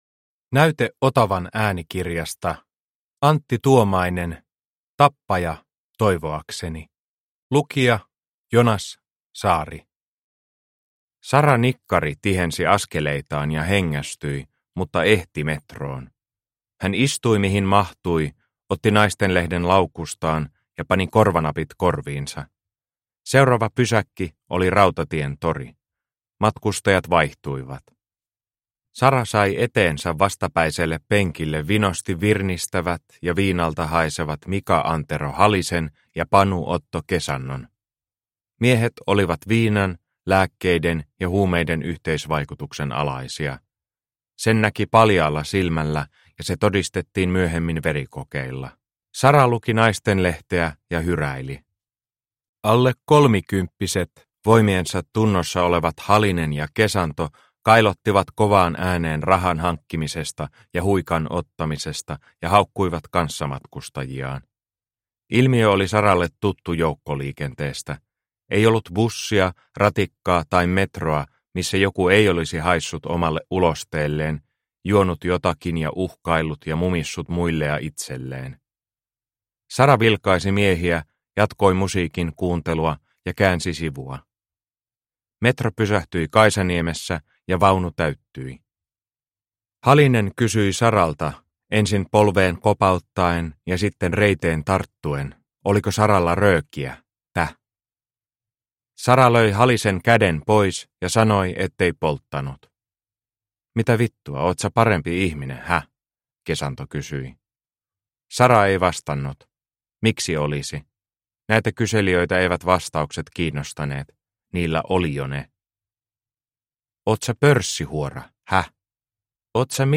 Tappaja, toivoakseni – Ljudbok – Laddas ner